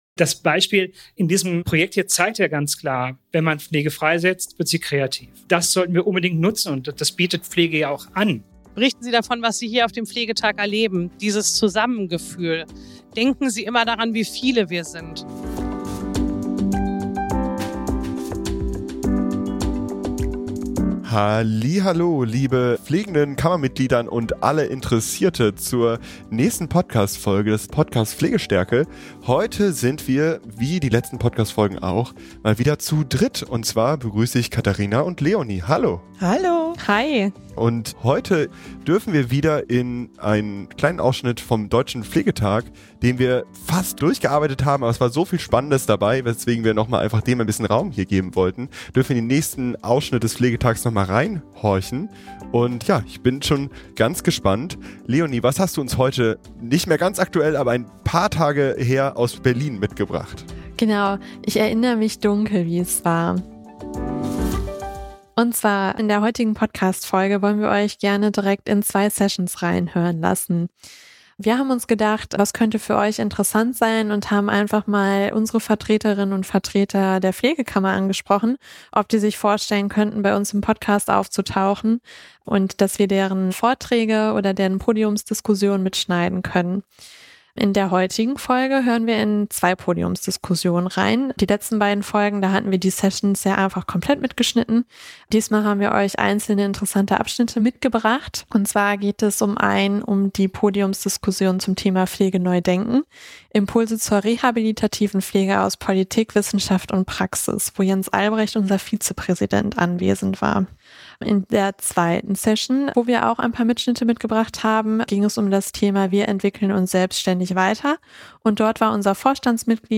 Was ist möglich, wenn Pflegefachpersonen mehr Freiraum und Mitbestimmung bekommen? Das ist das Thema unserer Sonderfolge des Podcasts Pflegestärke vom Deutschen Pflegetag 2024.